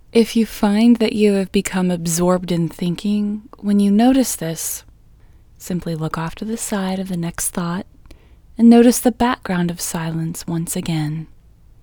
QUIETNESS Female English 14